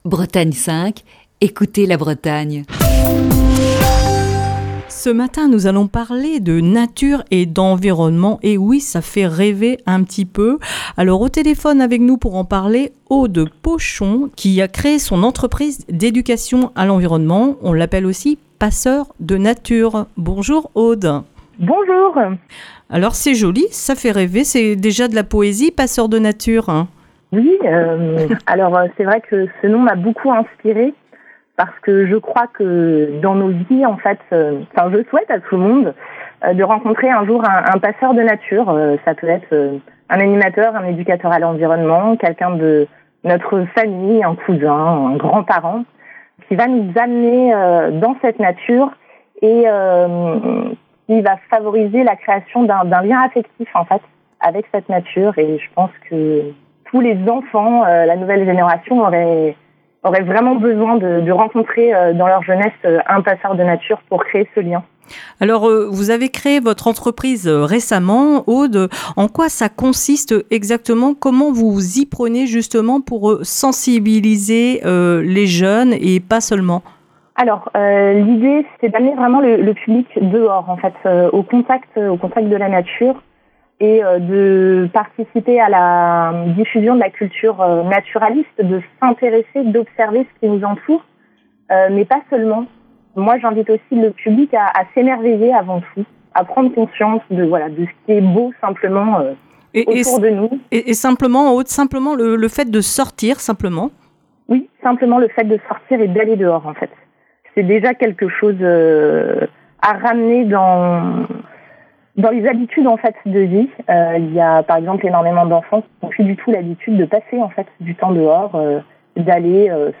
Dans le coup de fil du matin de ce jeudi